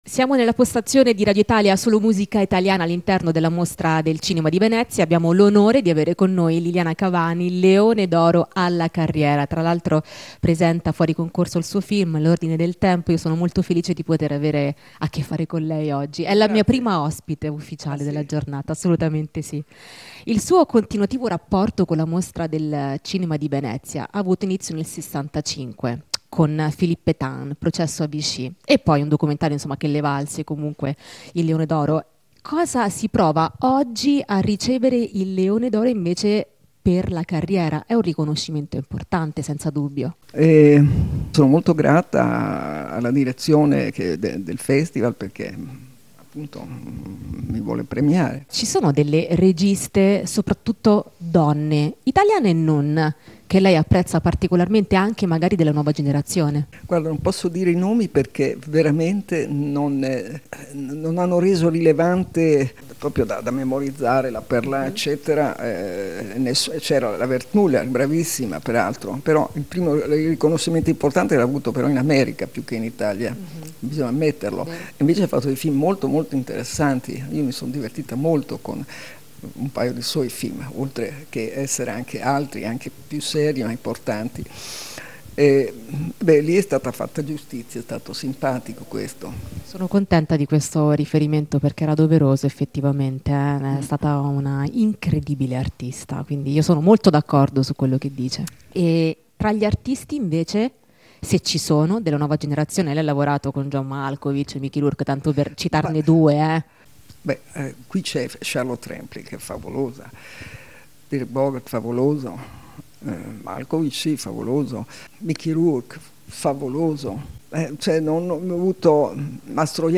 Lina Wertmüller nel corso dell'intervista ai nostri microfoni (che siamo radio ufficiale della Mostra Internazionale d'Arte Cinematografica - La Biennale di Venezia).